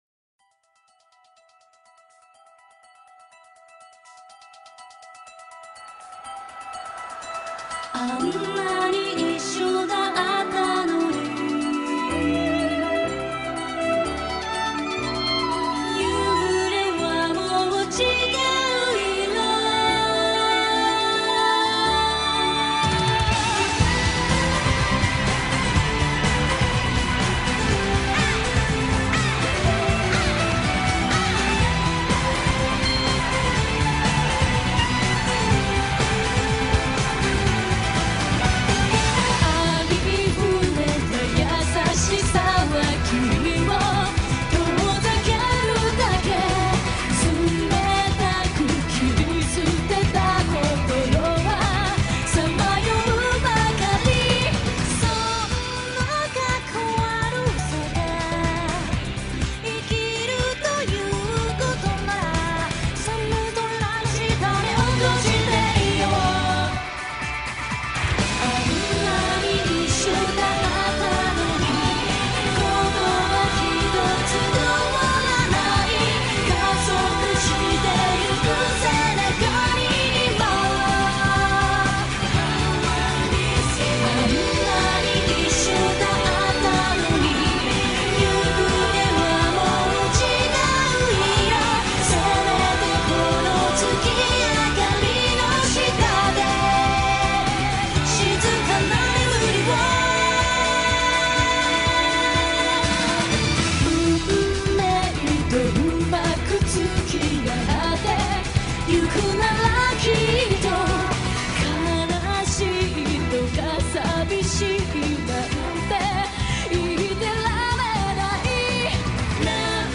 plus they are a vocal harmony group.